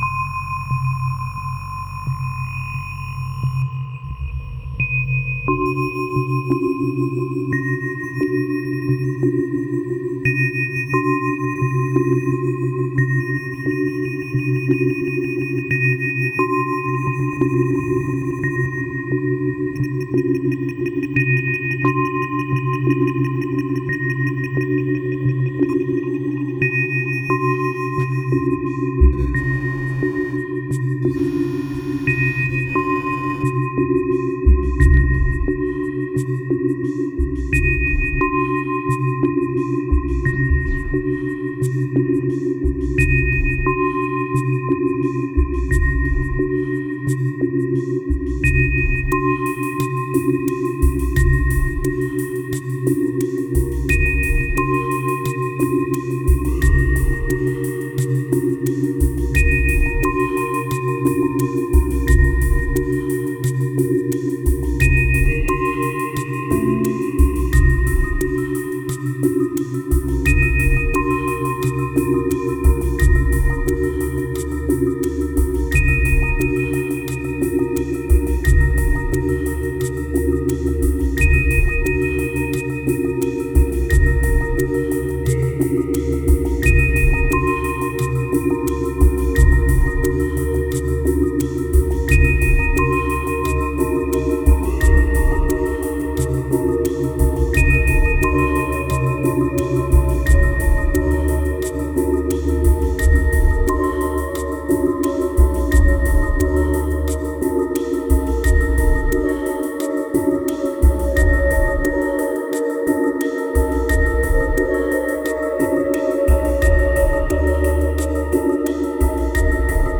1769📈 - 31%🤔 - 88BPM🔊 - 2014-05-02📅 - -36🌟